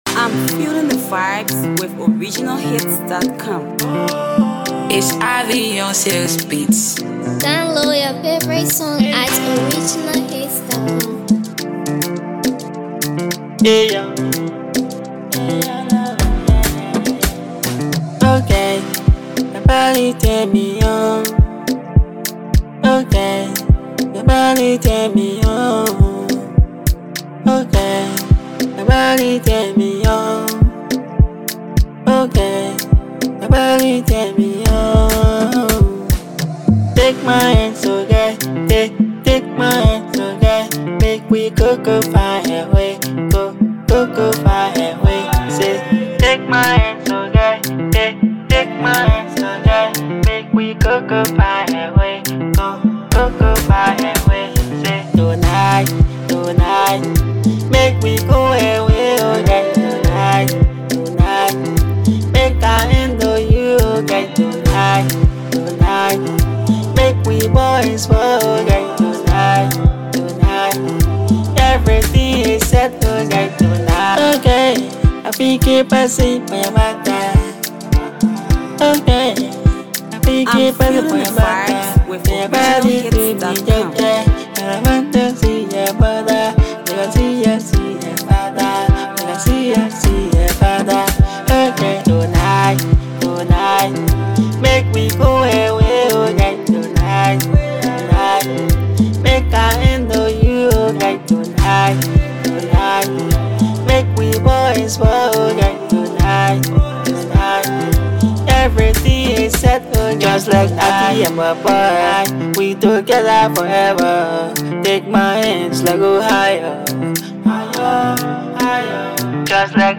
Production credit to the artist himself!